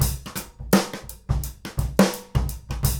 GROOVE 230MR.wav